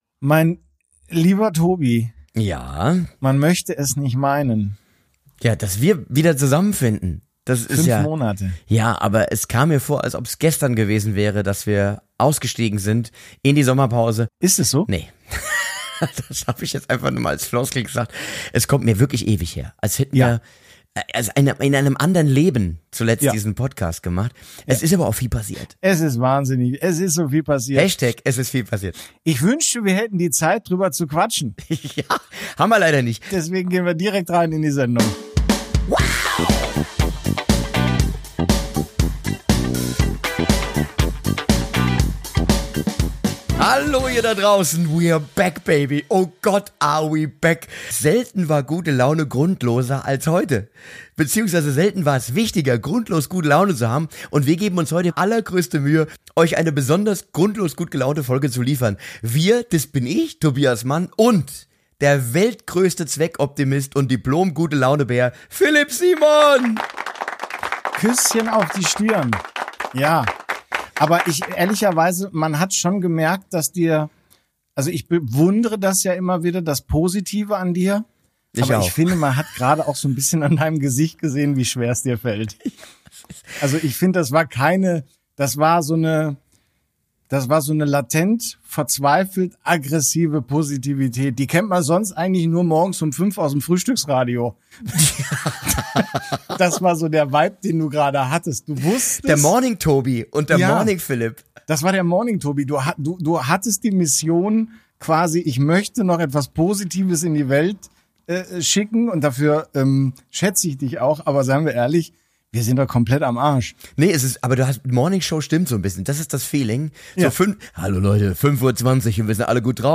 Die beiden Kabarettisten sind seit vielen Jahren befreundet und quatschen eh ständig über Gott und die Welt.